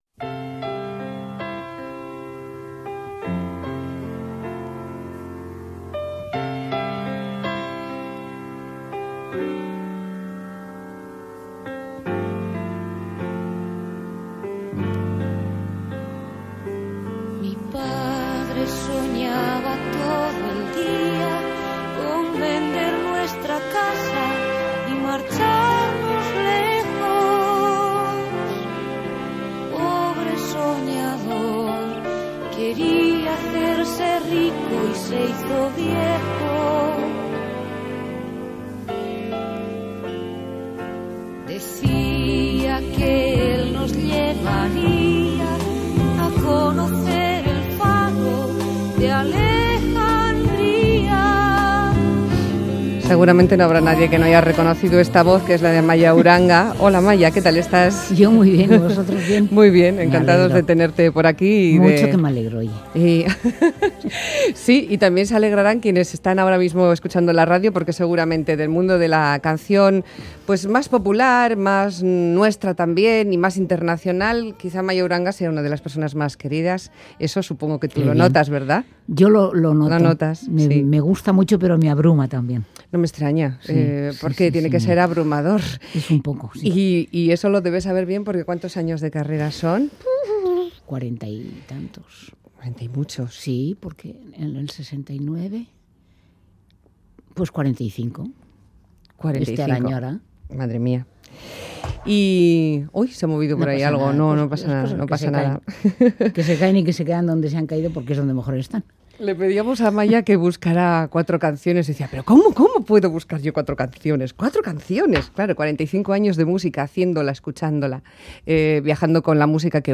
Radio Euskadi MÁS QUE PALABRAS Amaya Uranga, una vida de canciones Última actualización: 18/01/2015 11:40 (UTC+1) Nuestra entrevista de domingo nos descubre el lado más cercano y personal de Amaya Uranga. La que fuera voz de Mocedades, ahora de El Consorcio, nos atiende en vísperas de marcharse de gira por México.